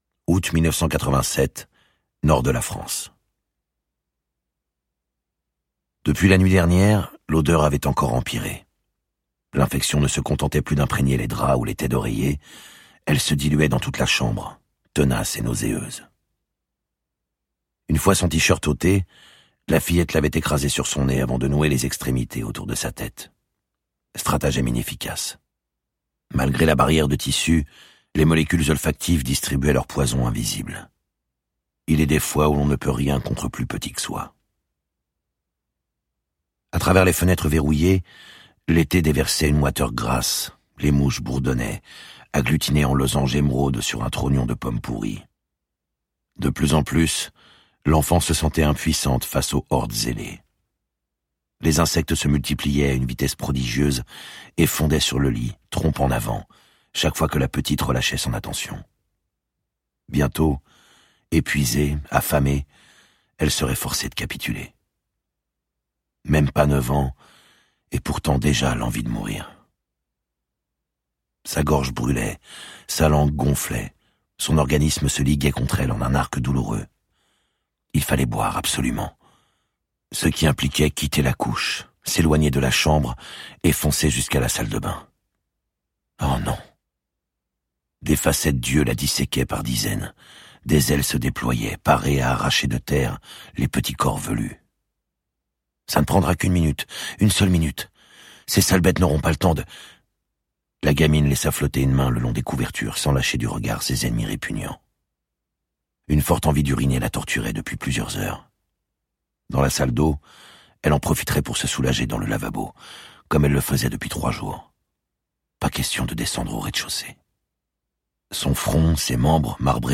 Extrait gratuit